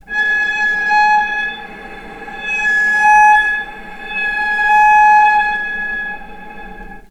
healing-soundscapes/Sound Banks/HSS_OP_Pack/Strings/cello/sul-ponticello/vc_sp-G#5-mf.AIF at a9e67f78423e021ad120367b292ef116f2e4de49
vc_sp-G#5-mf.AIF